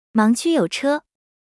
audio_car_watchout.wav